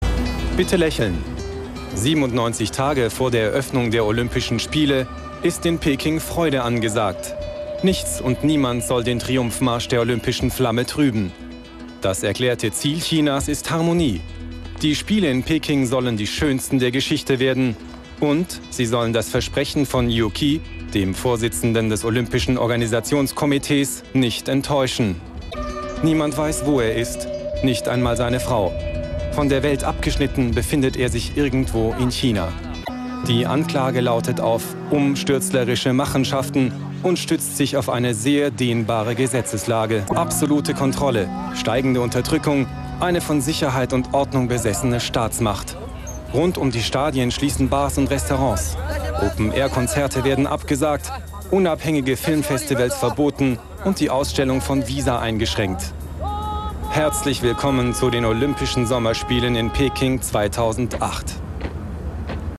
Professioneller deutscher Sprecher für Voice-Over, Reportage Synchron Werbung Doku Lernsoftware Telefon Präsentationen.
Kein Dialekt
Sprechprobe: eLearning (Muttersprache):
german voice over artist